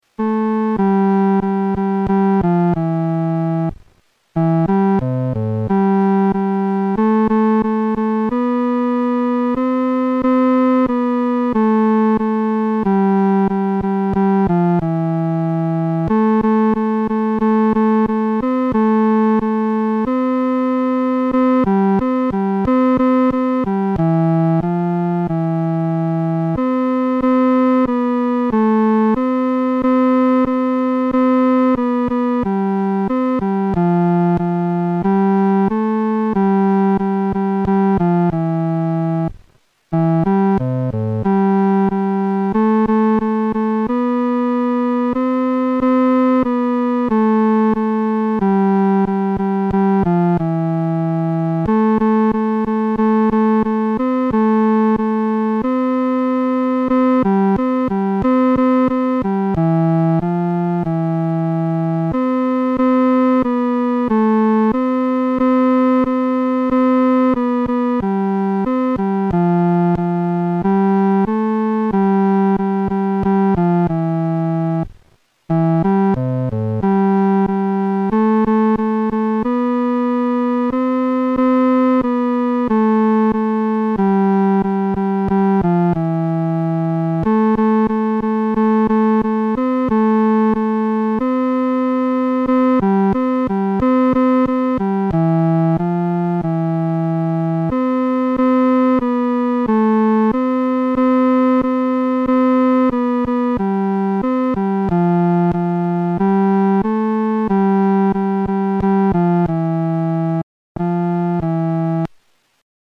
男高
本首圣诗由网上圣诗班录制
诗班在练习这首诗歌时，要清楚这首诗歌的音乐表情是欢庆地。